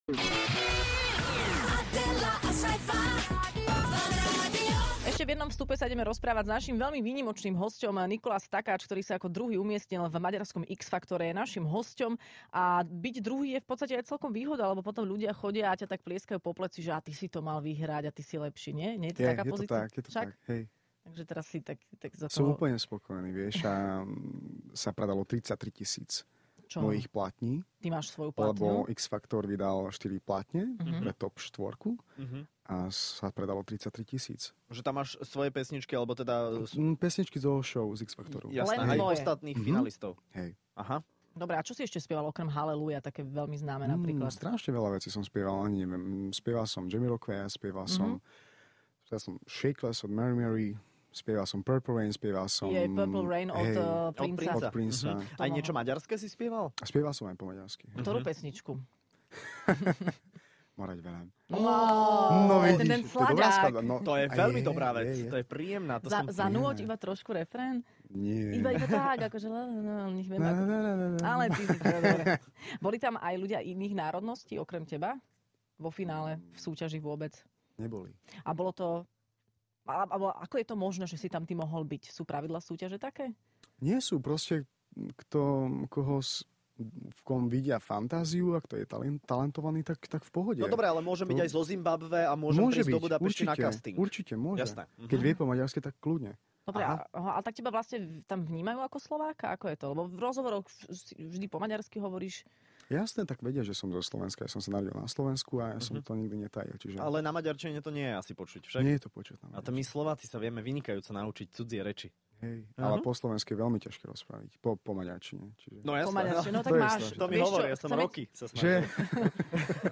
Hosť